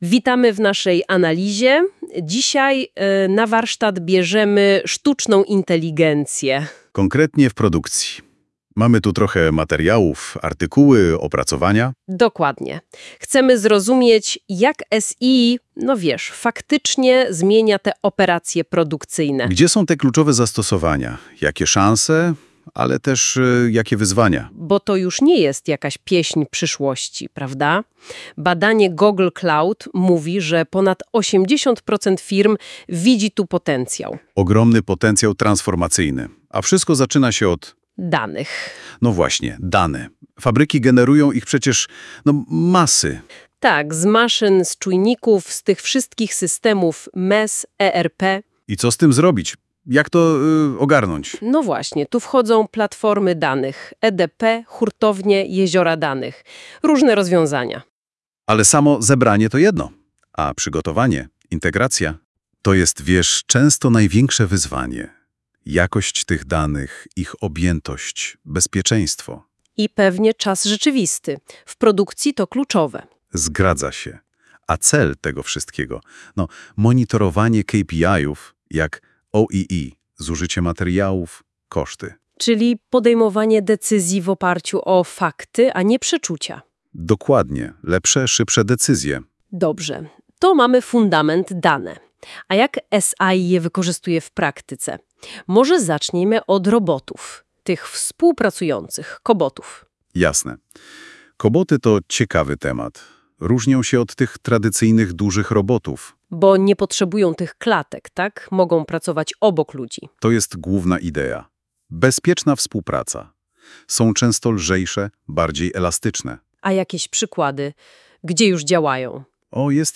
Wygenerowaliśmy rozmowę AI na podstawie naszych zebranych materiałów.